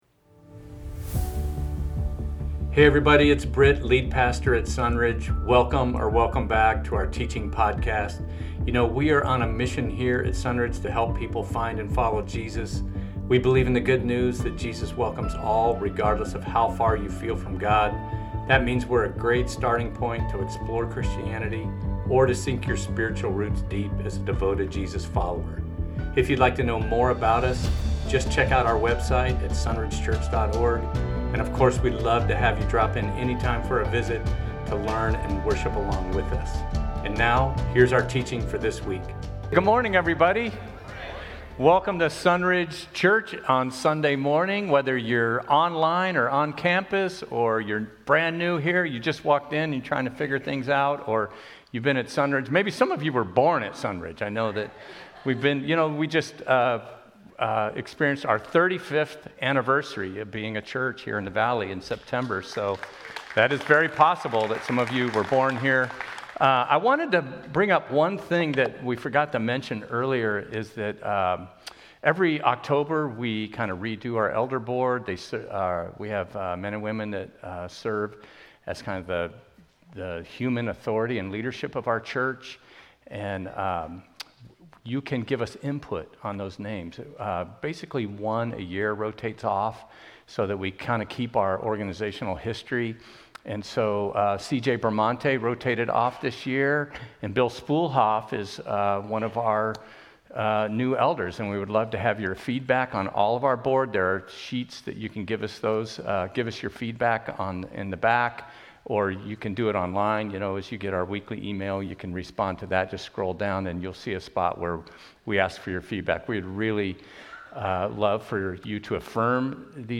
Rebuilding Justice - Sermons at Sunridge Church in Temecula.
Sermon Audio